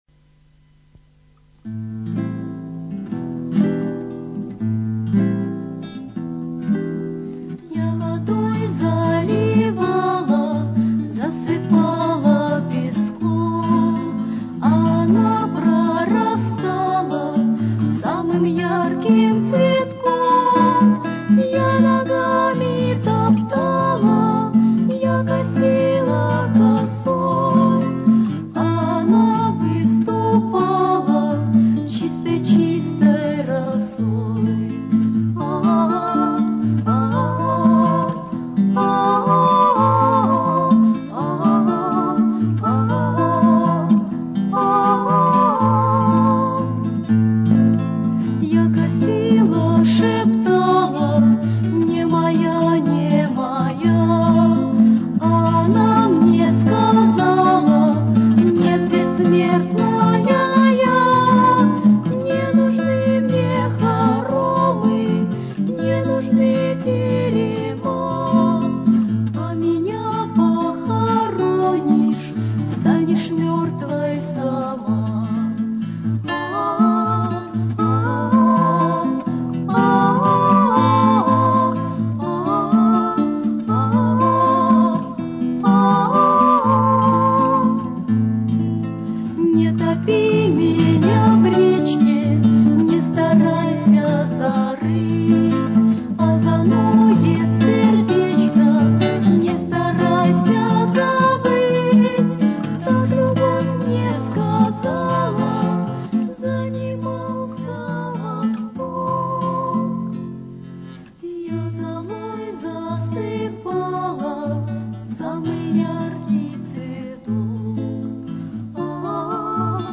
- песня пожарников smile
Дуэт